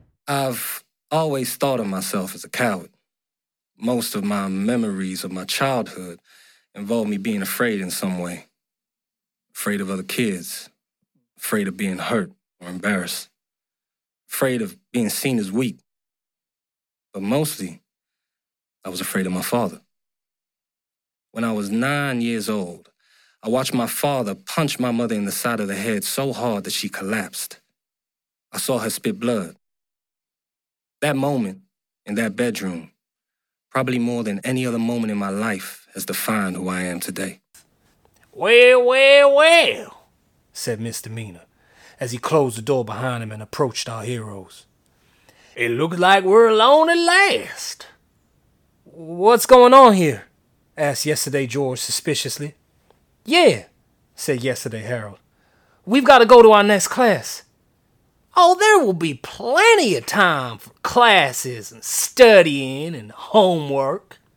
US Reel
The ultimate London boy-next-door, he oozes confidence while imbuing any script with an audible smile.